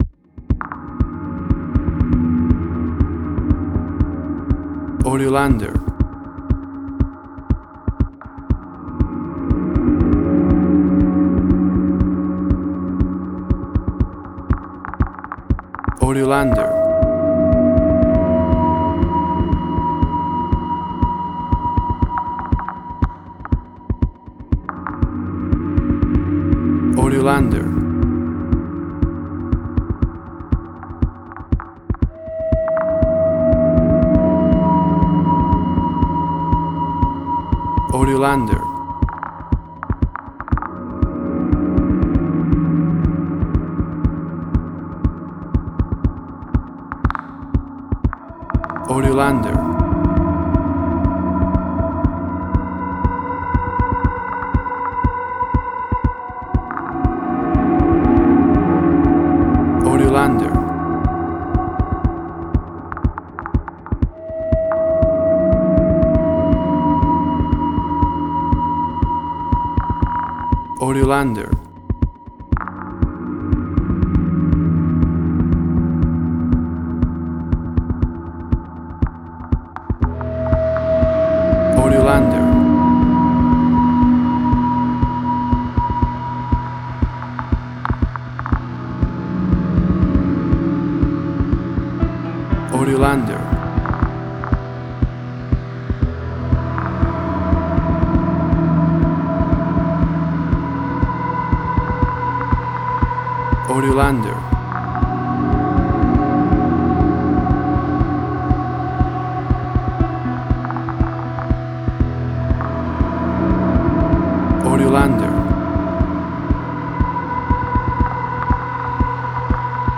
Steampunk Sci-fi, tension, suspense, action.
Tempo (BPM): 120